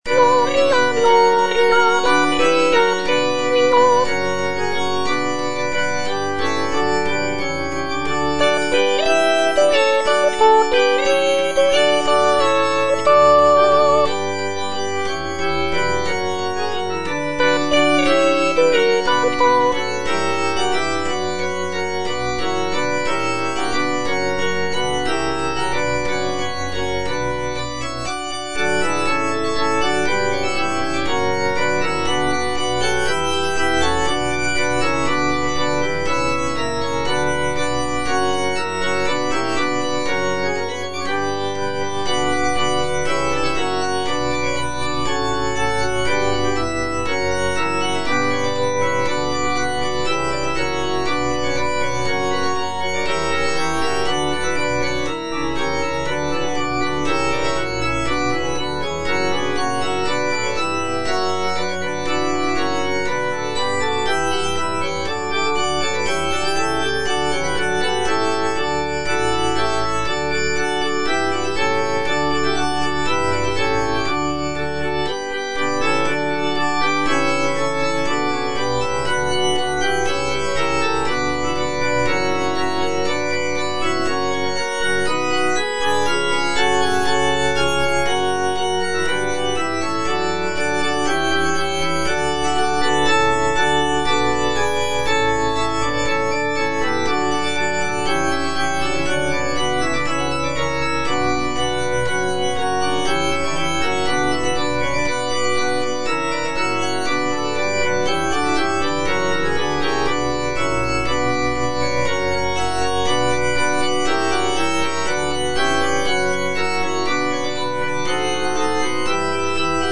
M.R. DE LALANDE - CONFITEBOR TIBI DOMINE Gloria Patri (petit choeur - soprano II) (Voice with metronome) Ads stop: auto-stop
"Confitebor tibi Domine" is a sacred choral work composed by Michel-Richard de Lalande in the late 17th century.